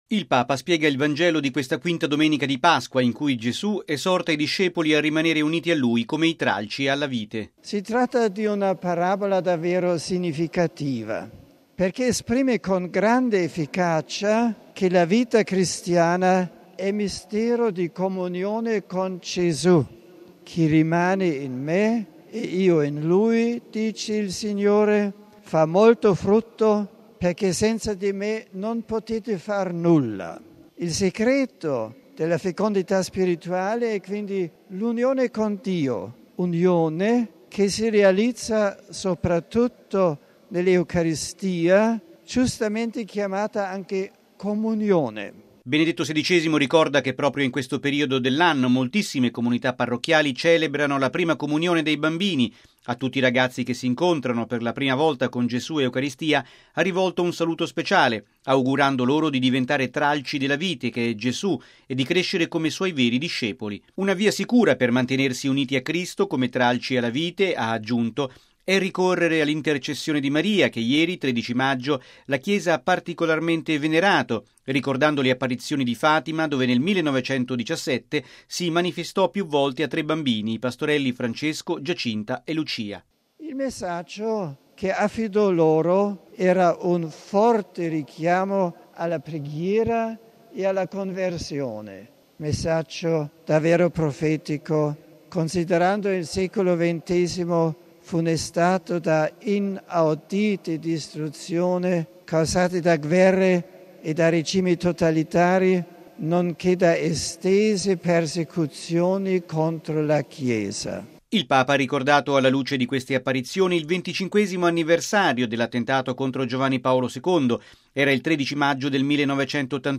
(15 maggio 2006 - RV) Il Papa ieri, durante il Regina Coeli in Piazza San Pietro, ha parlato del Vangelo della V Domenica di Pasqua, la parabola della vera vite. Quindi ha ricordato il 25 ° anniversario dell'attentato a Giovanni Paolo II e la promessa della Vergine a Fatima: nonostante le preoccupazioni per il futuro dell'umanità il suo Cuore Immacolato trionferà.